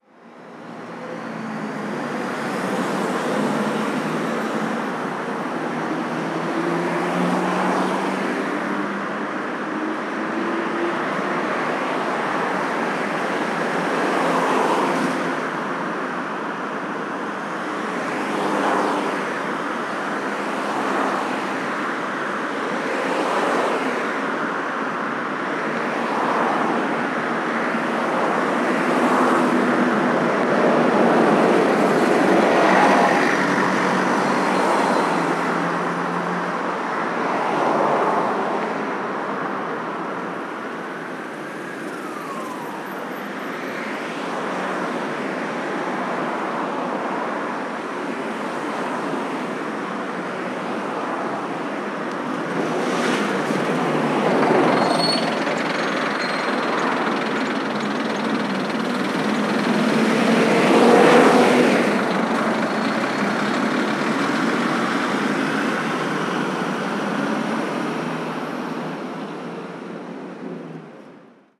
Ambiente del Paseo de la Castellana, Madrid
tráfico acelerar ambiente auto automóvil parada calle circulación ciudad coche contaminación desplazamiento medio de transporte moto motocicleta motor movilidad ruido sirena sonido
Sonidos: Transportes Sonidos: Ciudad